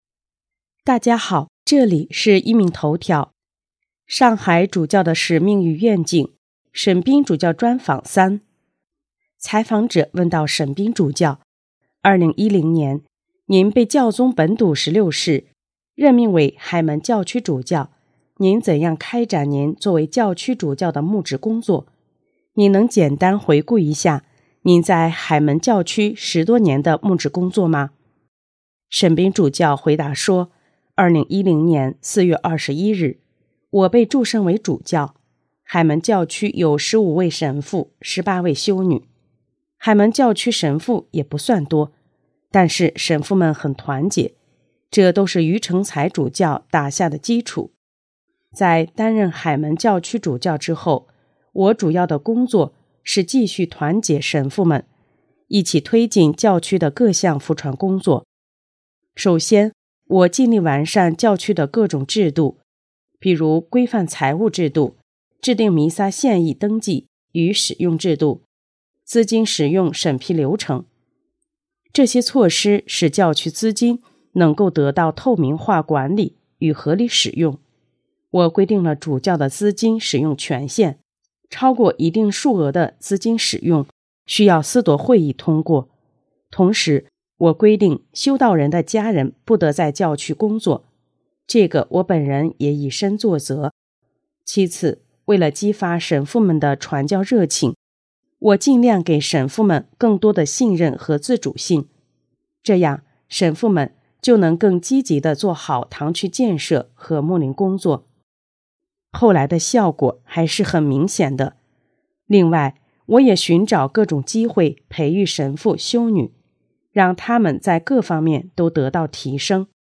【壹明头条】| 上海沈斌主教专访(三):回顾在海门教区十多年的牧职工作